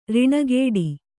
♪ riṇagēḍi